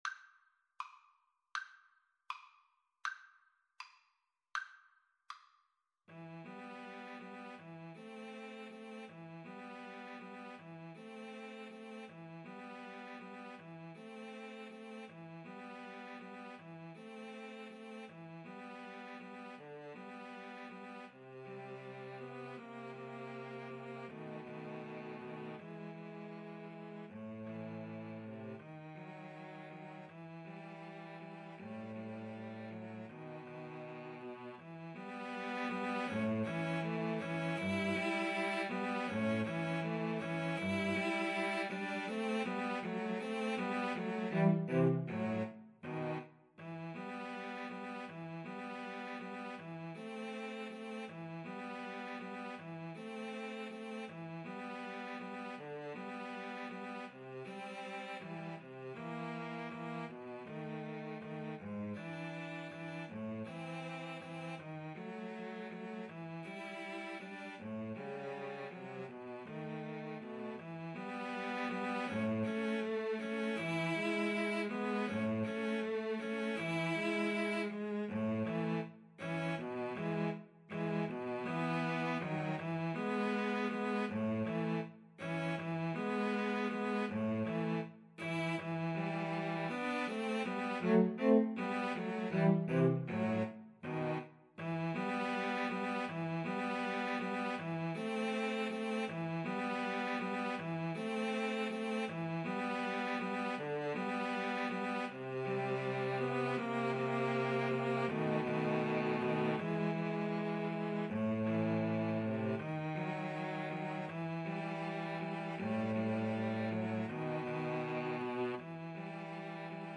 Play (or use space bar on your keyboard) Pause Music Playalong - Player 1 Accompaniment Playalong - Player 3 Accompaniment reset tempo print settings full screen
E minor (Sounding Pitch) (View more E minor Music for Cello Trio )
~ = 100 Andante
Classical (View more Classical Cello Trio Music)